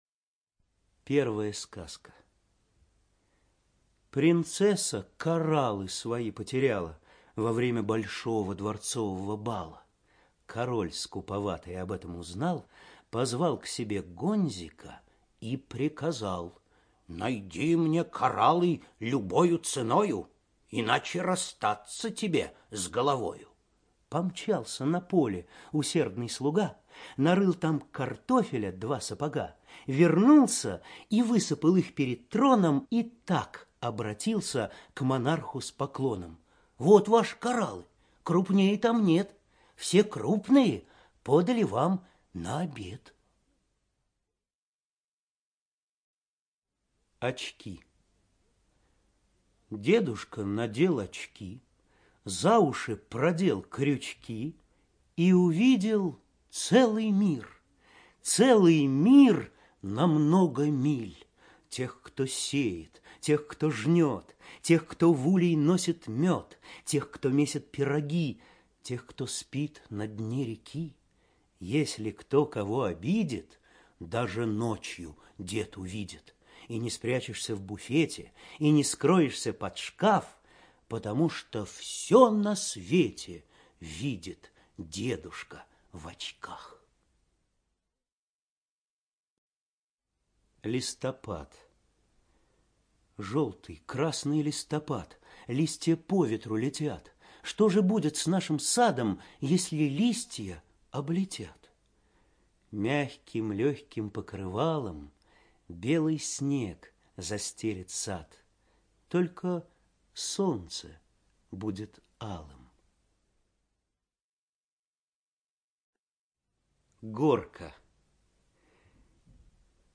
ЧитаетАбдулов В.
ЖанрСказки